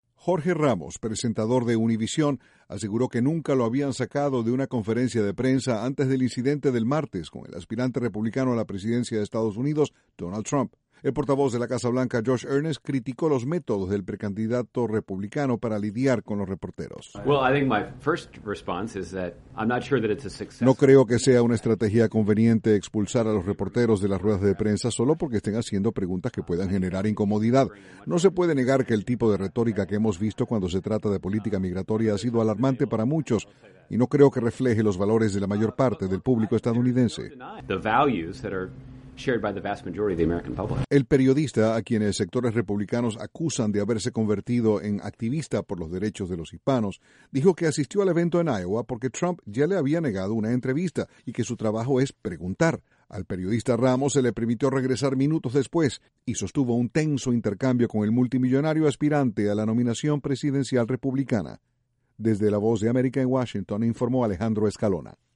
La Casa Blanca hizo comentarios sobre el incidente entre el precandidato presidencial republicano Donald Trump y el periodista de la cadena Univisión, Jorge Ramos. Desde la Voz de América, Washington